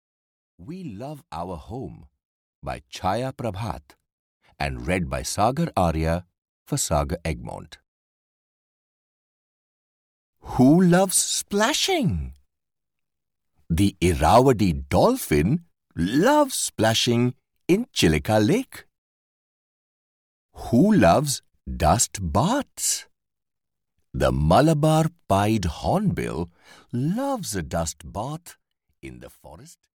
We Love Our Home (EN) audiokniha
Ukázka z knihy